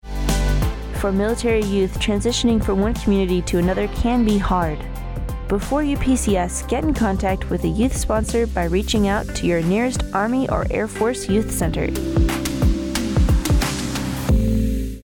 Radio Spot - KMC Youth Centers